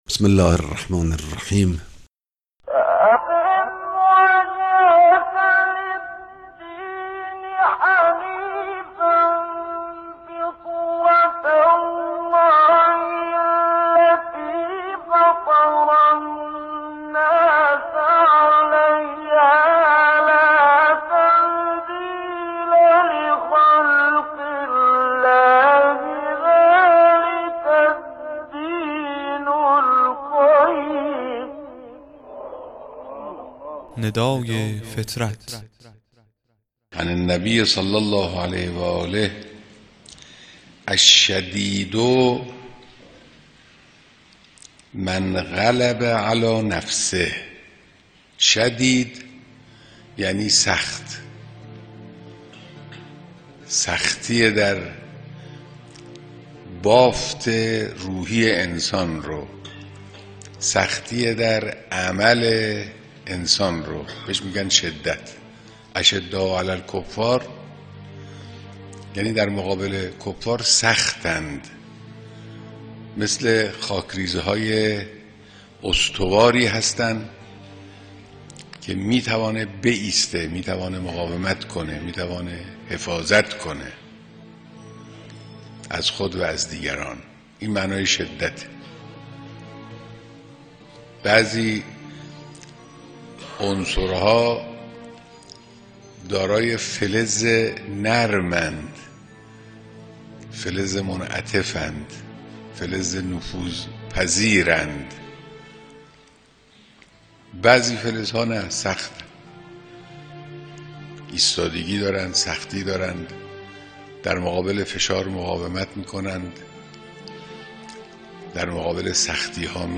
در این قطعه صوتی از امام خامنه ای، رتبه خود را با معیاری که بیان می شود، بسنجیم